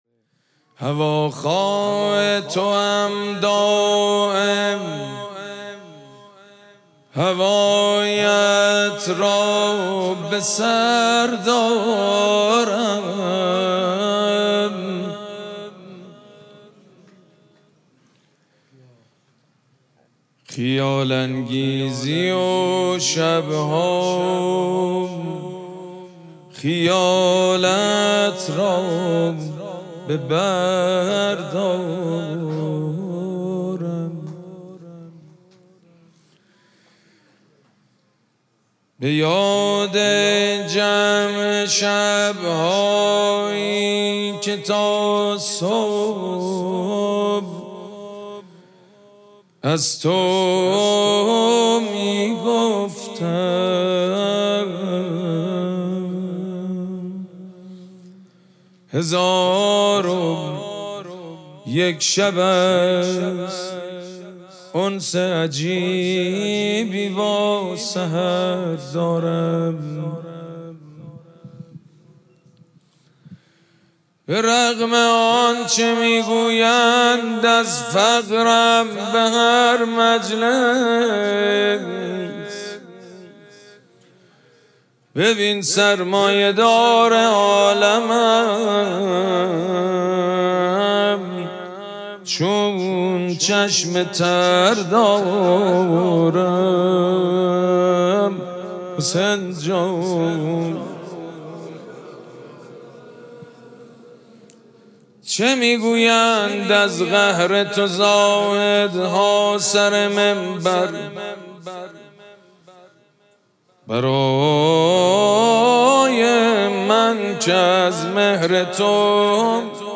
شب اول مراسم جشن ولادت سرداران کربلا
شعر خوانی
مداح
حاج سید مجید بنی فاطمه